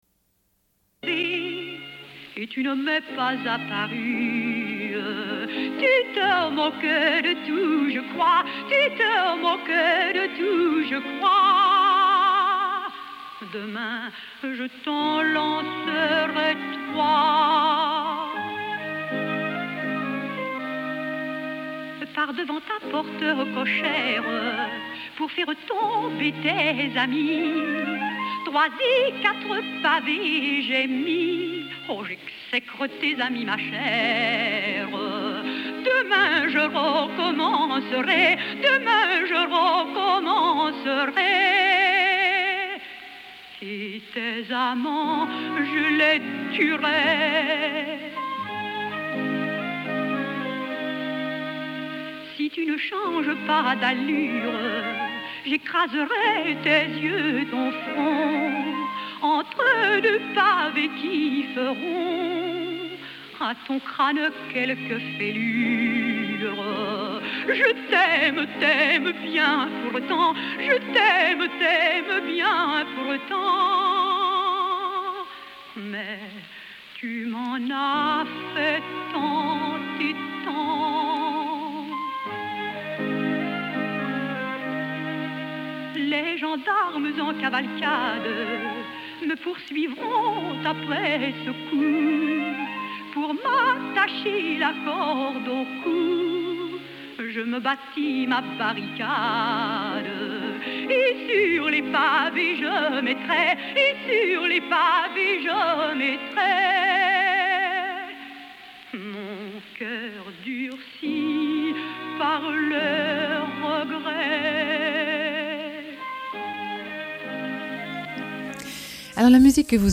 Une cassette audio, face B28:50